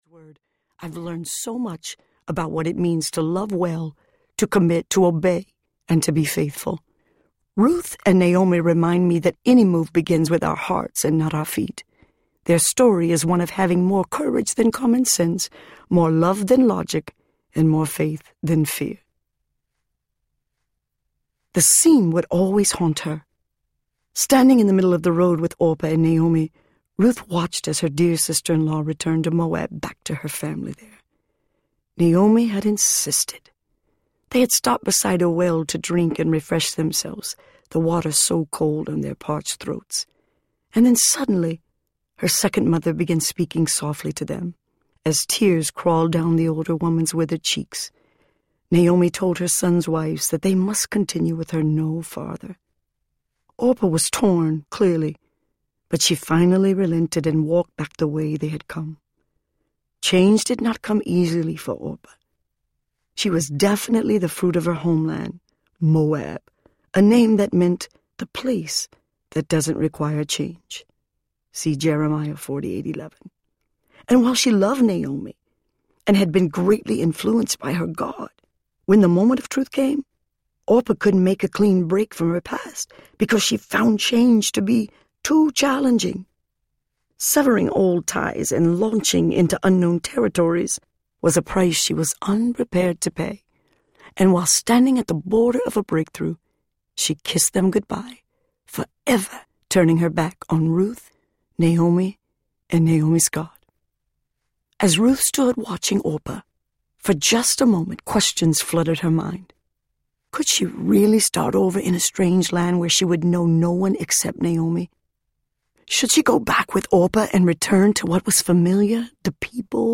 You Have It In You Audiobook
Narrator
6 Hrs. – Unabridged